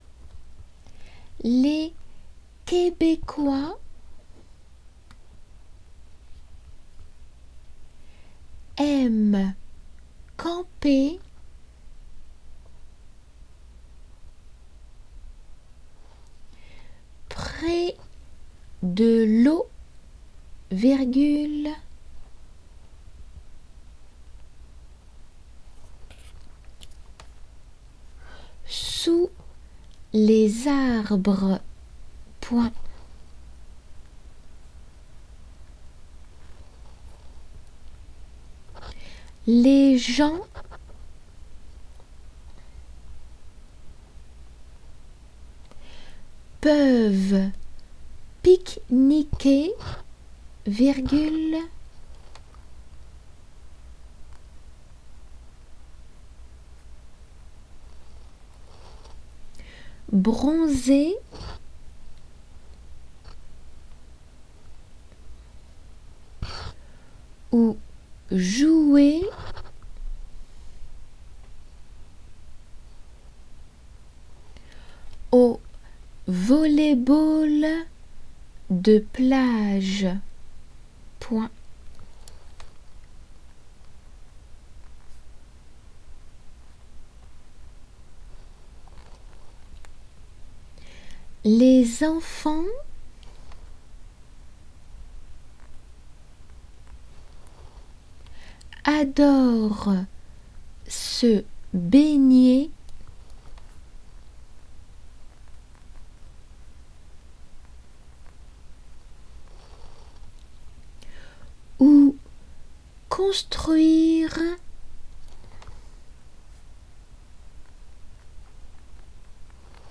実際の仏検の時は普通の速さで２回、　そしてデイクテ用に１回読まれ
デイクテの速さで  　　　　　練習用に吹込みの際雑音少々が入って居ます。